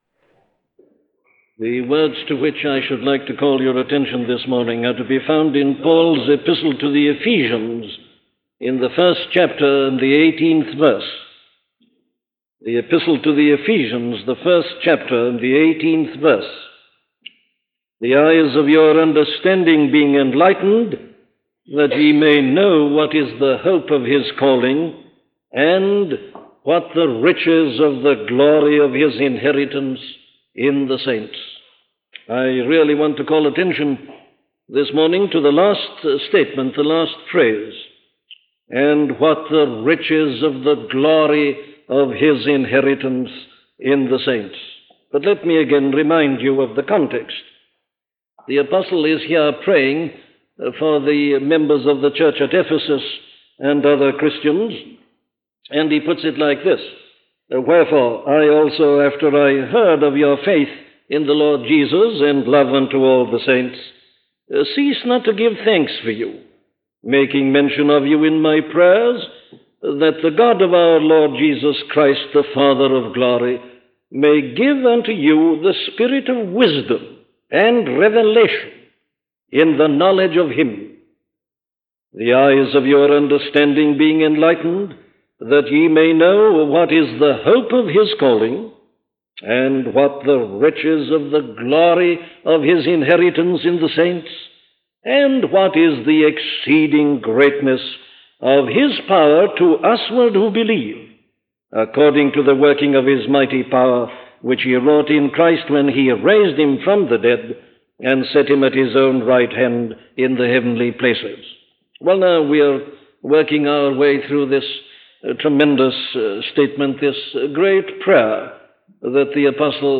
Free Sermon | Book of Ephesians | Chapter 1 | Page 2 of 2
An audio library of the sermons of Dr. Martyn Lloyd-Jones.